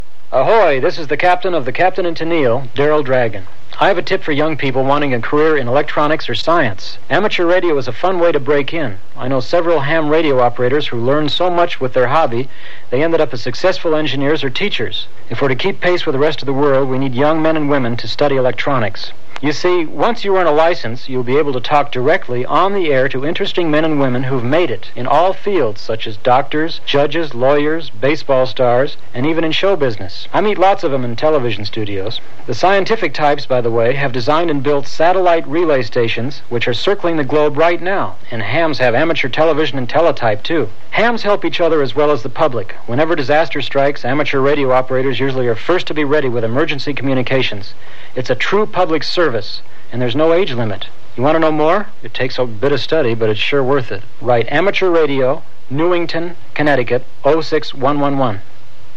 public service announcements
Daryl Dragon radio commercial for amateur radio, 1979, MP3, 1.3MB